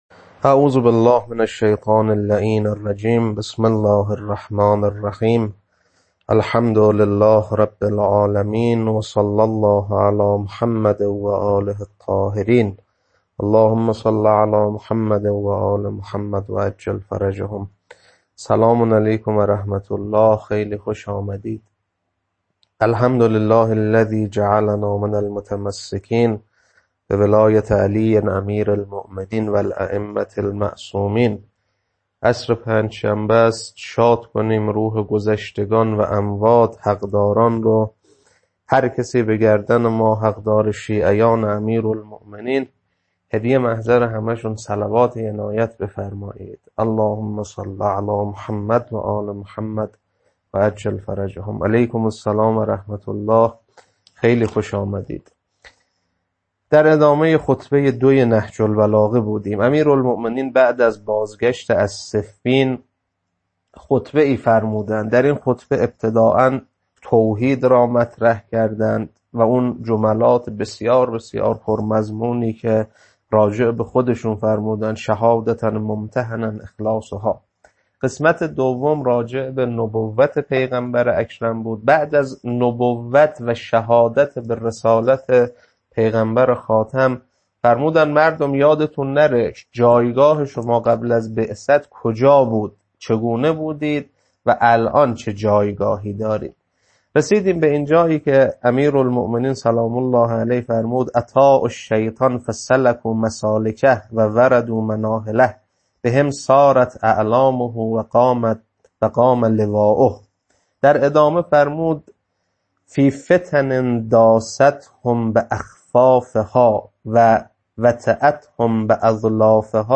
خطبه 2 بخش دوم.mp3
خطبه-2-بخش-دوم.mp3